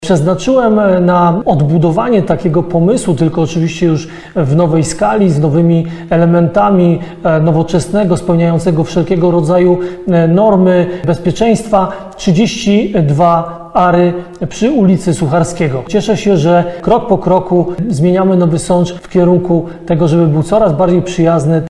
Znajdzie się na działce o powierzchni 32 arów – mówił Ludomir Handzel, prezydent Nowego Sącza.